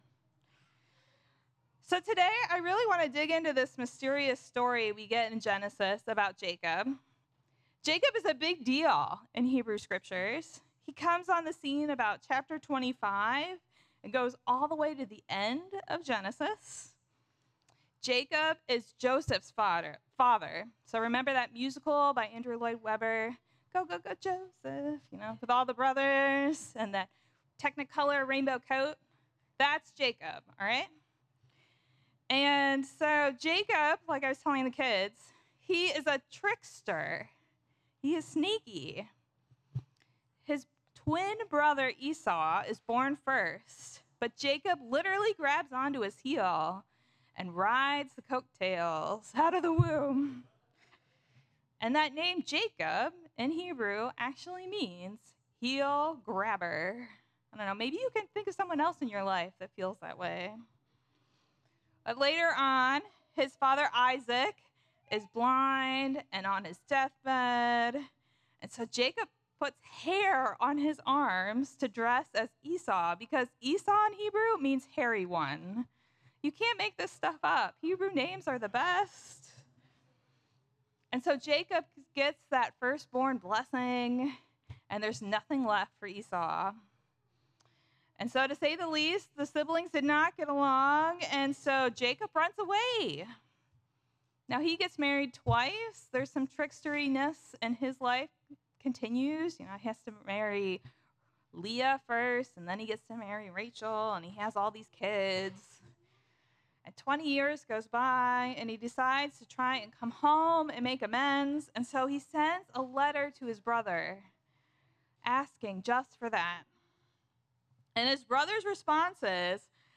FLC Sermons